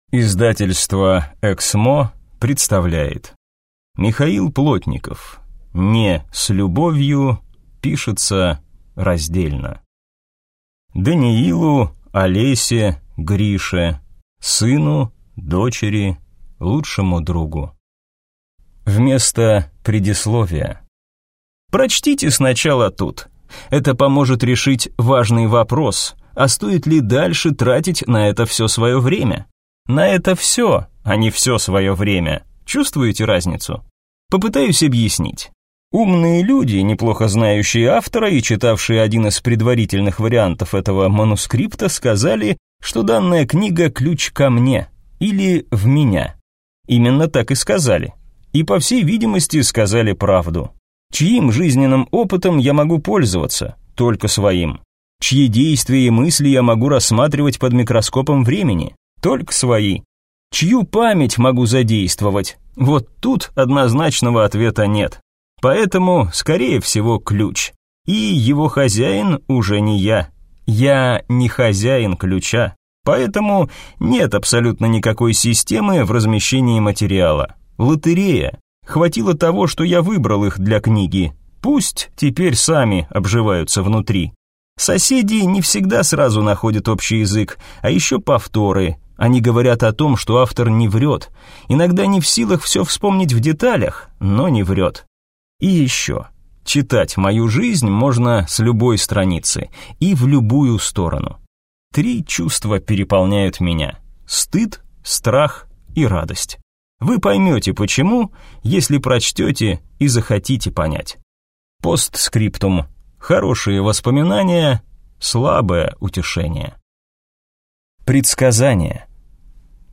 Аудиокнига Не с любовью пишется раздельно | Библиотека аудиокниг